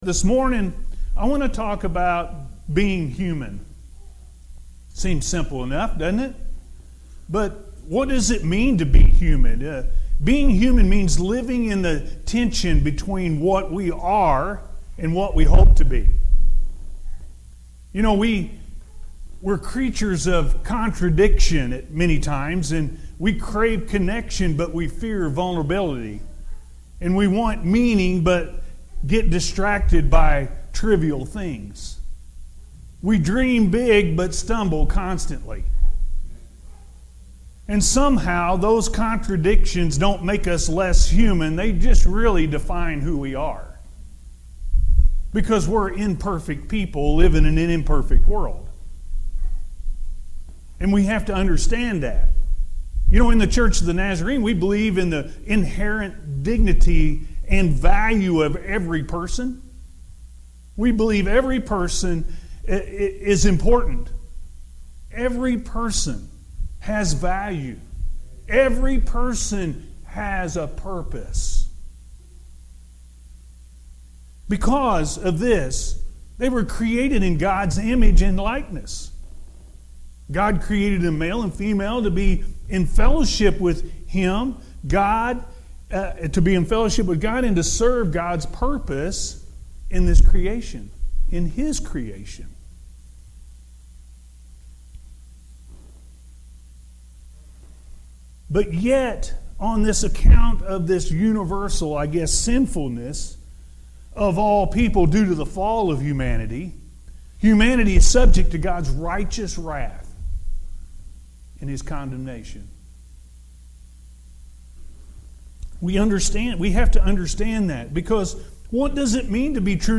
Being Human-A.M. Service – Anna First Church of the Nazarene